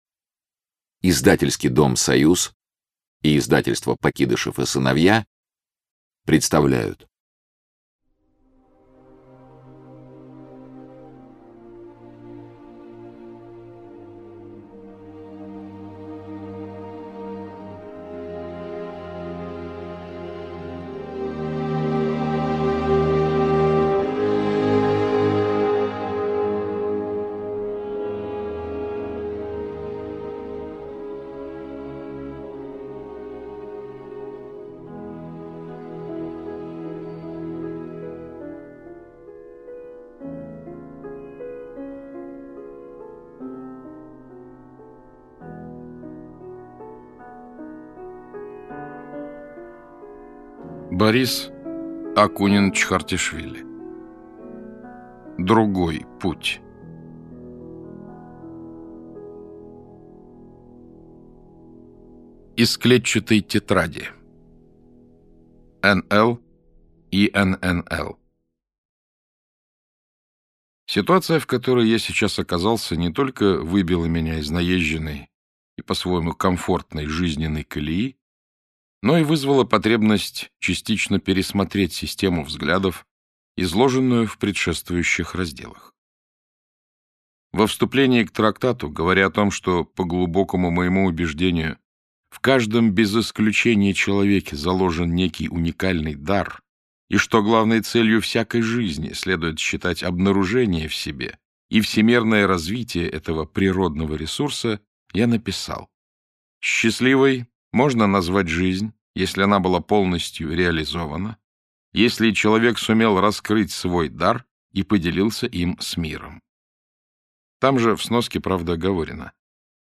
Аудиокнига Другой Путь
Борис Акунин продолжает «Аристономию» – книгу, ставшую, по признанию автора, «первым серьезным романом» в его жизни. Как и предыдущая книга серии, «Другой путь» блестяще прочитан Народным артистом РФ Александром Клюквиным и повествует о мире личных отношений и Любви.